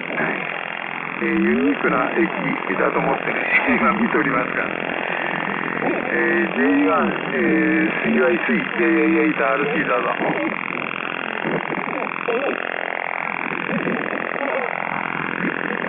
これは同じ3月9日の夕方6時半ころのノイズです。